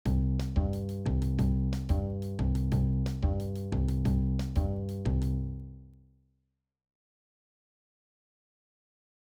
アフロ・キューバン
アフロ・キューバンはキューバ系のラテン。クラーベと呼ばれるリズムパターンが主となります
クラーベのベースとドラムのリズムパターン
アフロキューバン.wav